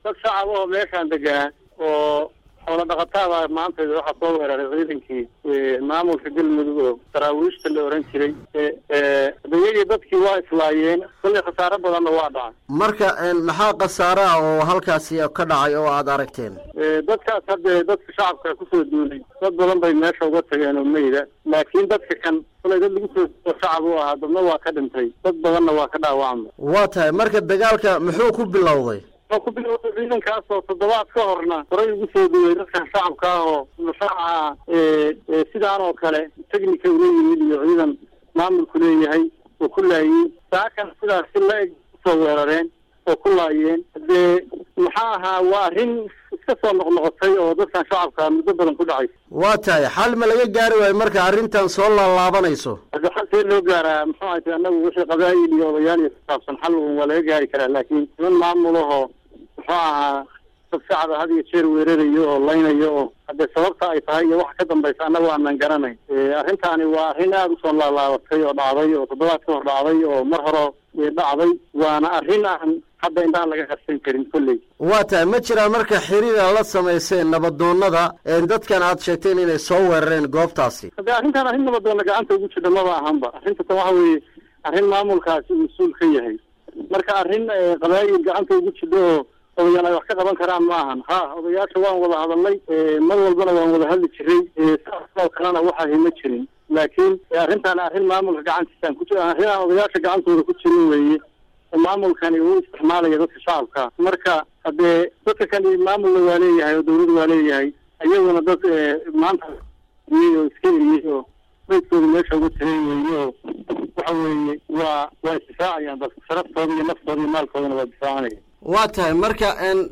Radio Gaalkacyo waraysi siiyay